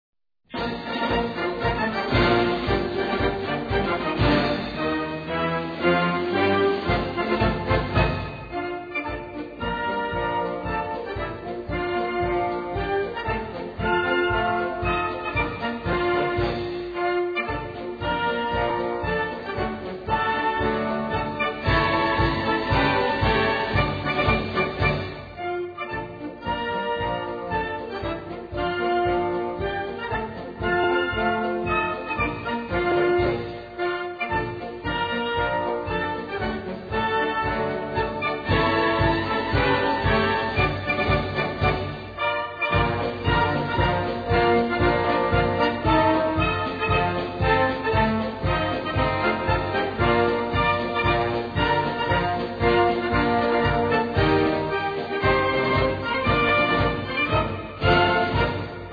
Gattung: Marsch
A5-Quer Besetzung: Blasorchester Zu hören auf